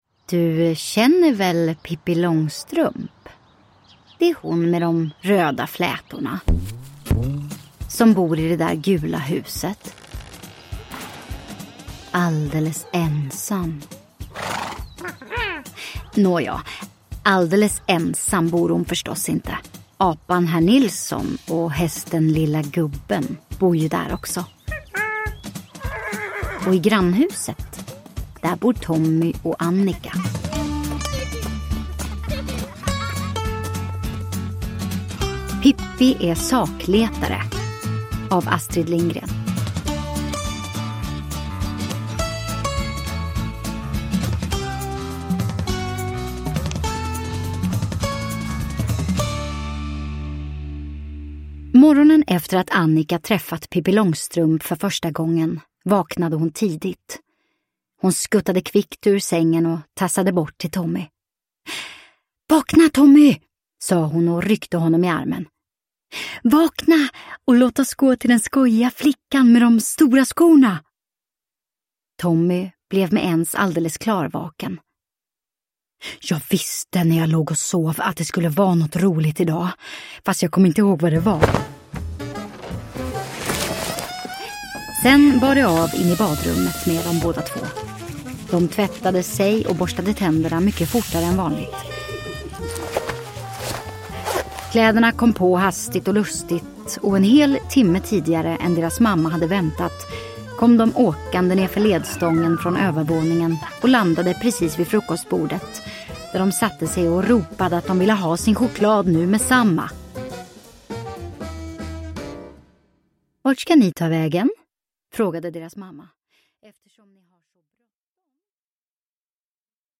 Pippi är sakletare (Ljudsaga) – Ljudbok
Ny inläsning av Pippi Långstrump med rolig ljudläggning!